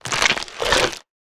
sounds / mob / camel / eat3.ogg
eat3.ogg